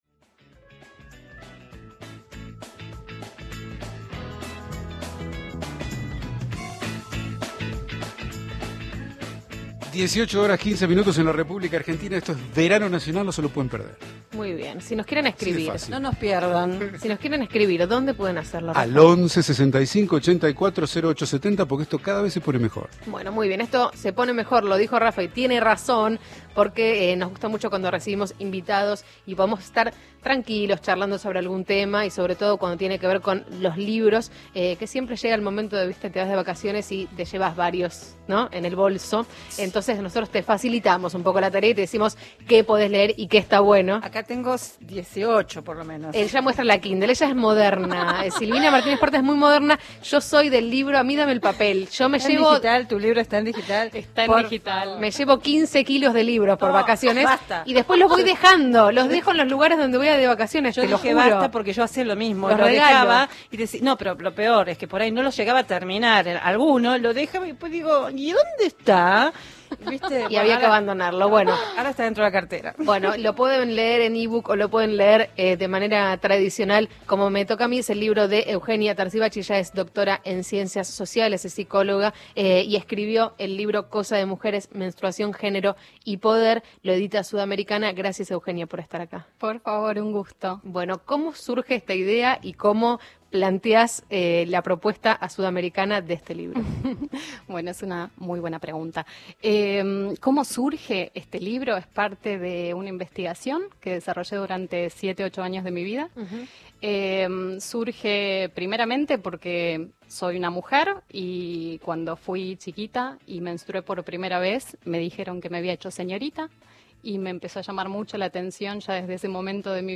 visitó la Radio y dialogó con la mesa de Verano Nacional acerca de desinformación, tabú, prejuicios, machismo y política sanitaria.